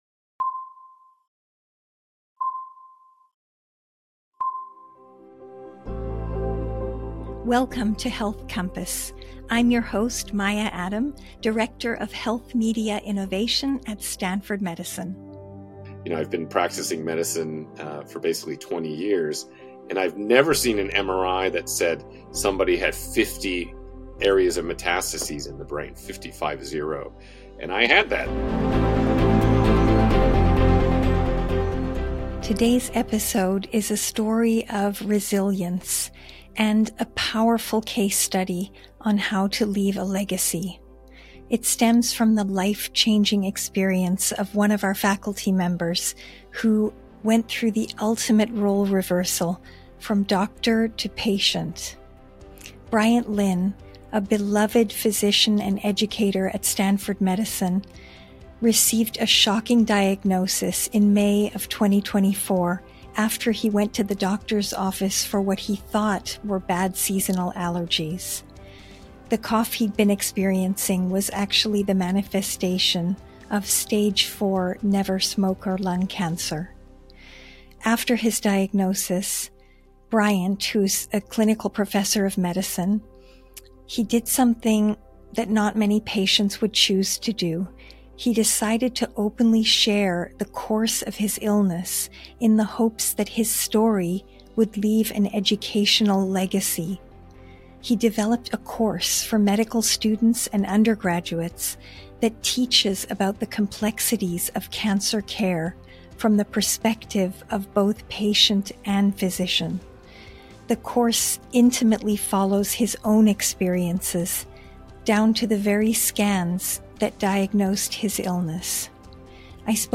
interviews leading experts on crucial health topics